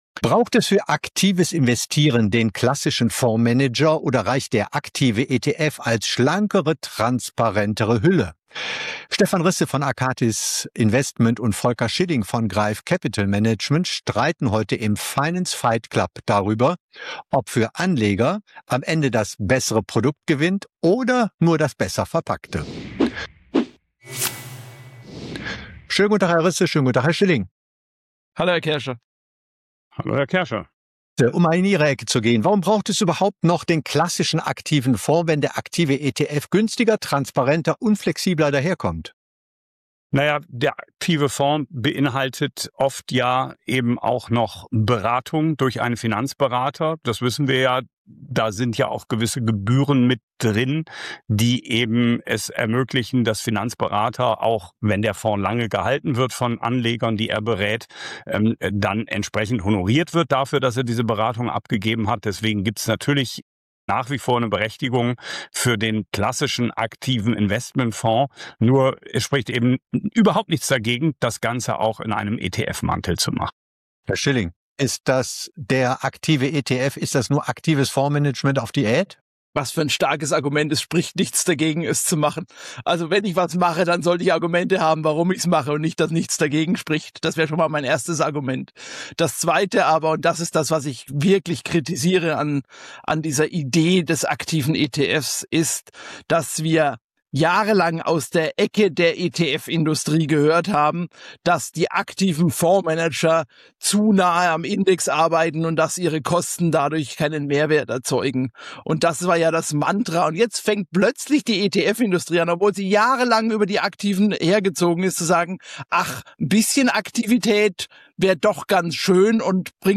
Im Finance Fight Club prallen zwei Sichtweisen frontal aufeinander: